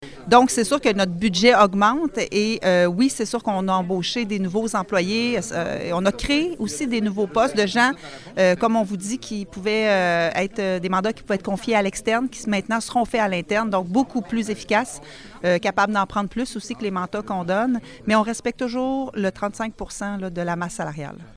La mairesse, Geneviève Dubois, a indiqué en séance du conseil lundi, que l’important est de respecter un ratio avec le budget.